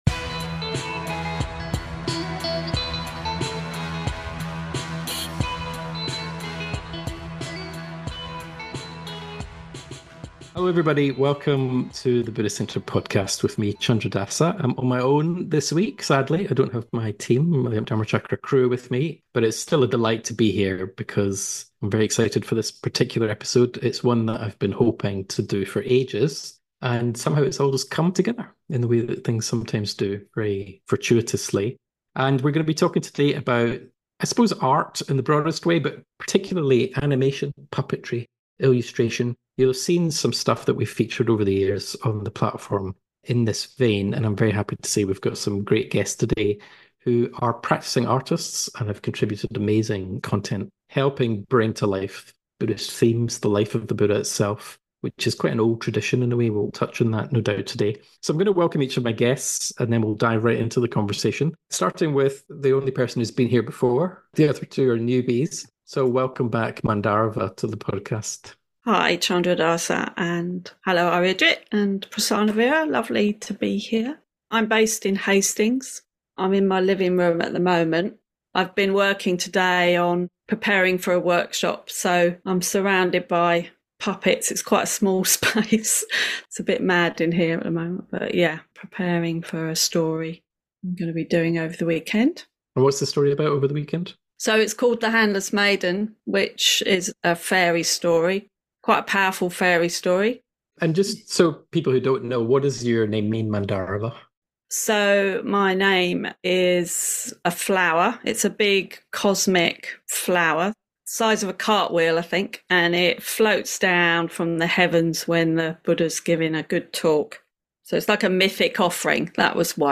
A joyous conversation today with Buddhist artists and practitioners about illustration, animation, puppetry, model making and art - how all of these can help bring to life the Buddhist path in the most beautiful, moving and inspiring ways!